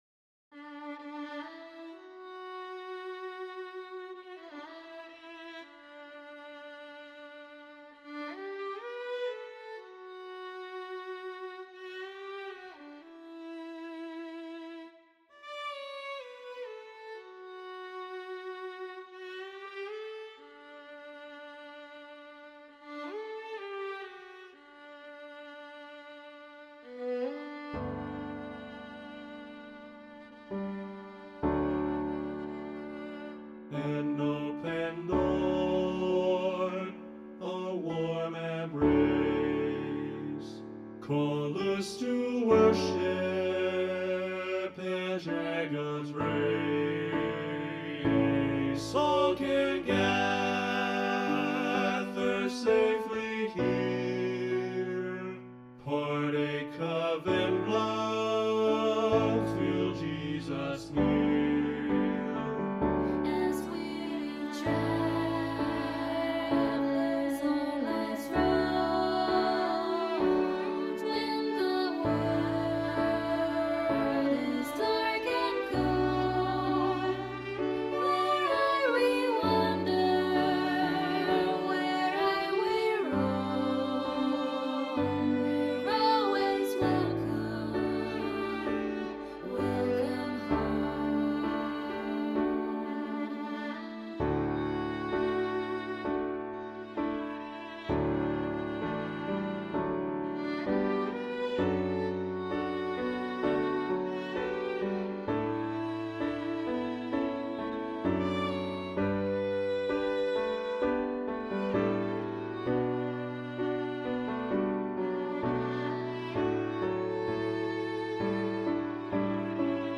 They play a beautiful melody, but it feels solitary.
Voicing/Instrumentation: SATB
Piano Violin